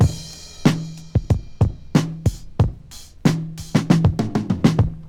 • 94 Bpm Drum Beat D# Key.wav
Free drum groove - kick tuned to the D# note. Loudest frequency: 529Hz
94-bpm-drum-beat-d-sharp-key-z1o.wav